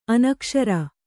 ♪ anakṣara